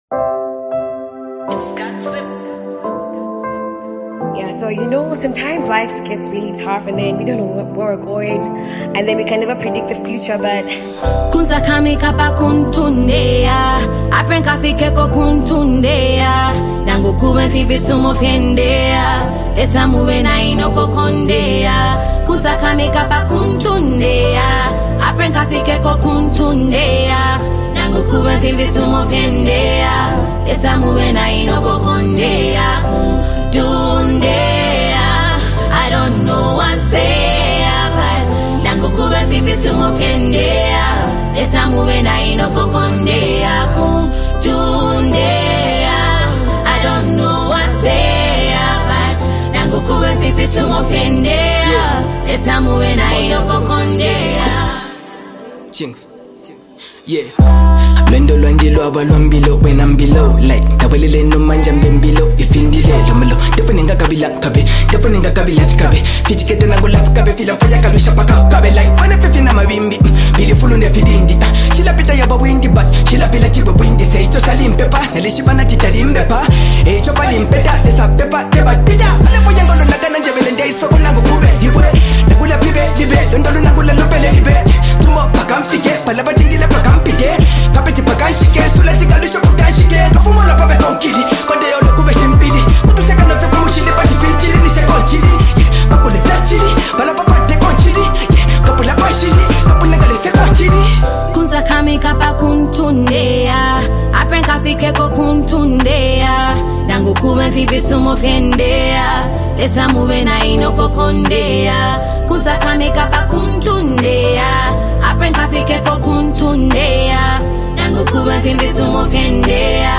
African hip-hop